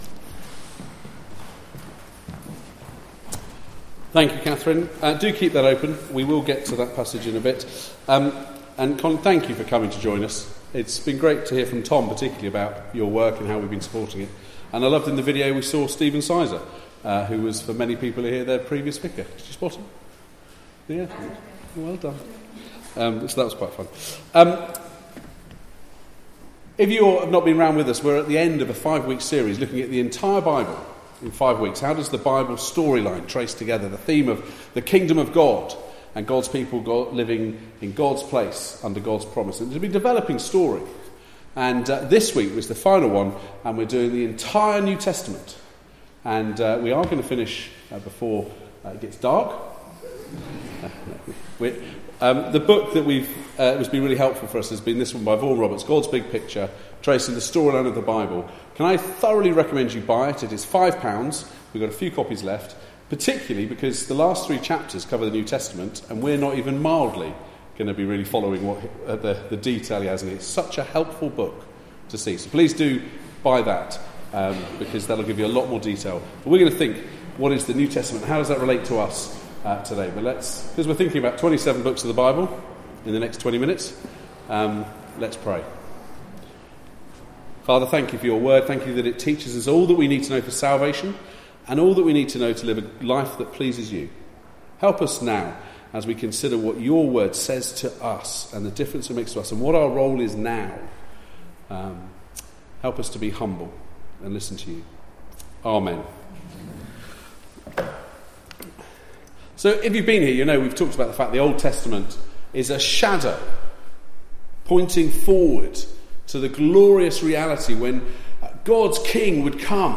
Passage: 2 Peter 3: 1-18 Service Type: Weekly Service at 4pm